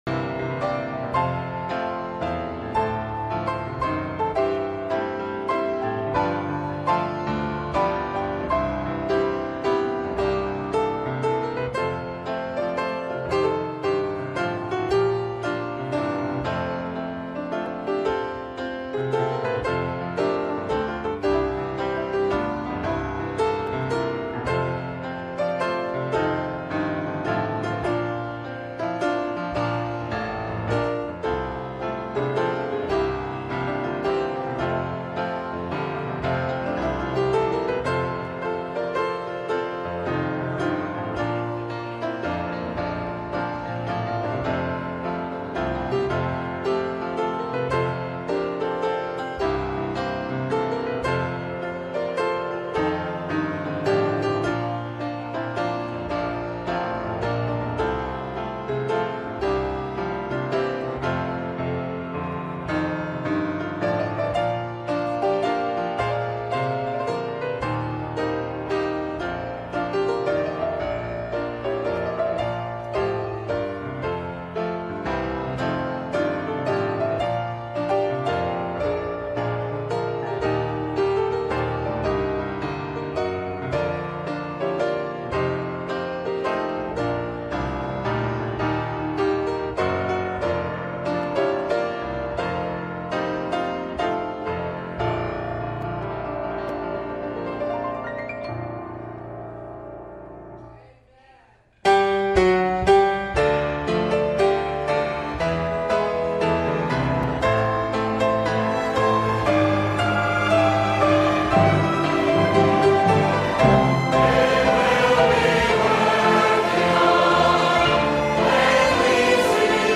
Live Sunday Evening Services